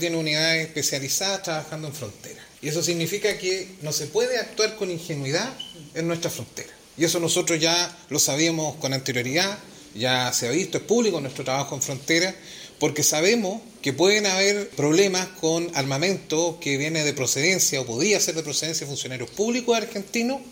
Se trata de un hecho detectado en la zona fronteriza de La Araucanía, en el sector de Licura en Lonquimay. Por lo mismo, el seremi de Seguridad, Israel Campusano, respaldó el trabajo de Carabineros indicando que no se puede actuar con ingenuidad.